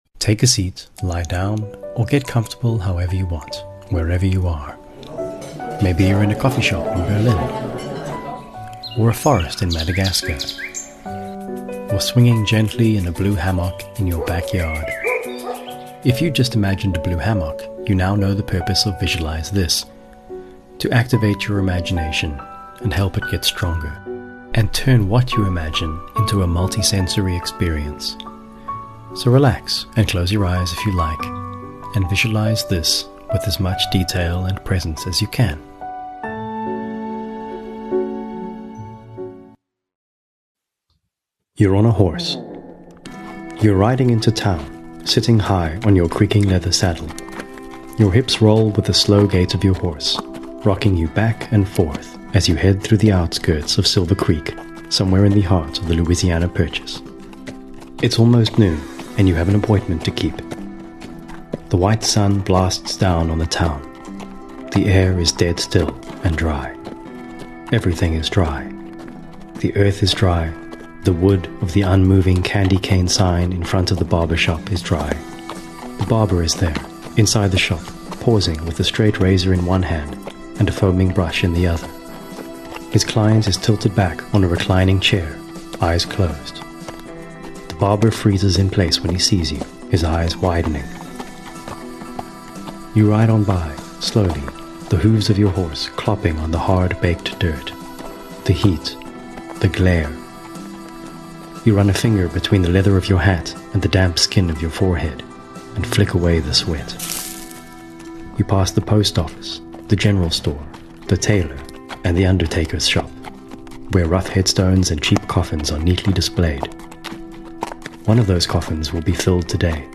Here's one of my first visualisations, remade with BANGBANG CLIPCLOP KABOOMS, by which I mean immersive sound effects and music.